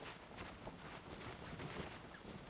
cloth_sail12.L.wav